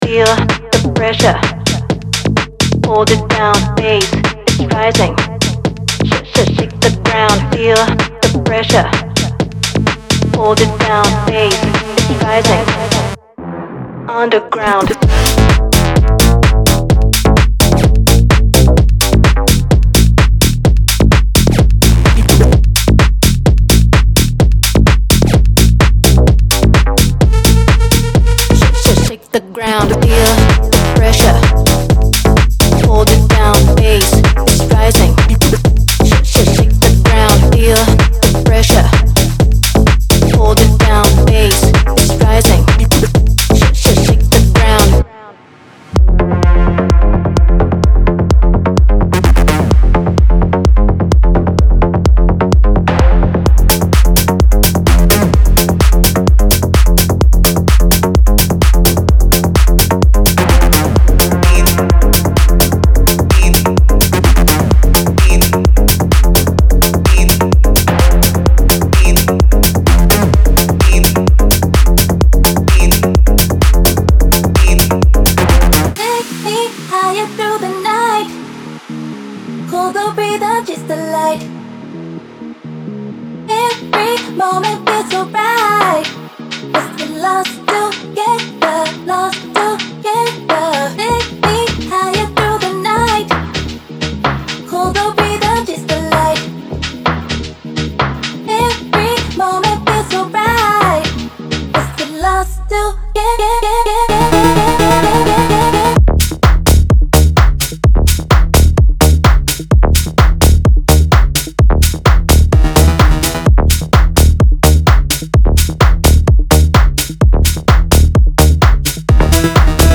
House Tech House